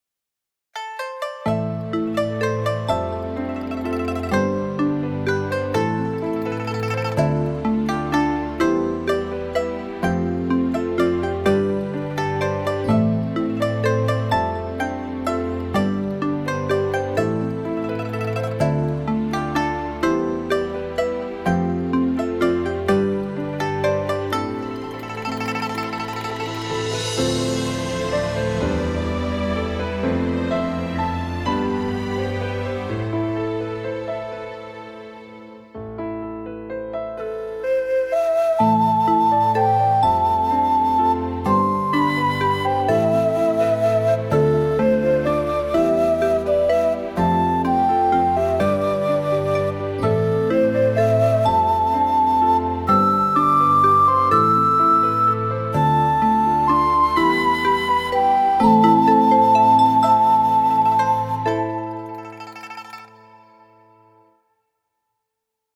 【１. 地圖背景音樂】